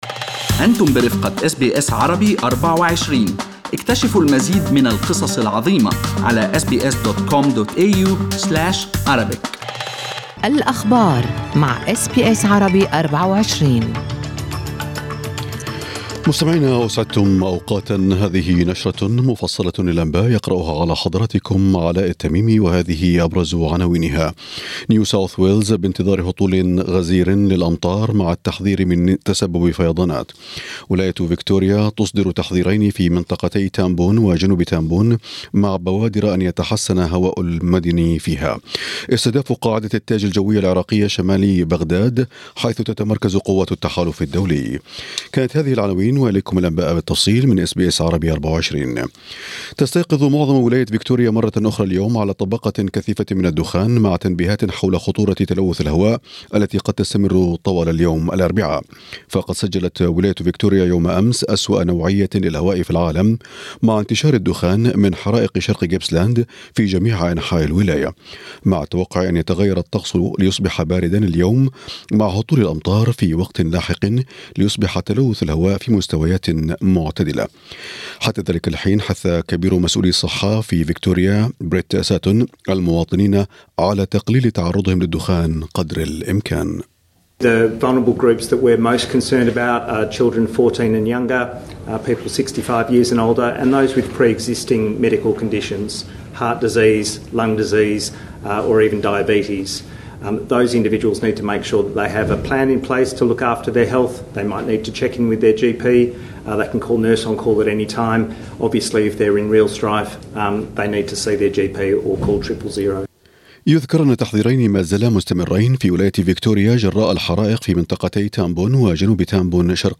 أخبار الصباح: دخان كثيف يغطي سماء فيكتوريا لكن الظروف في طريقها للتحسن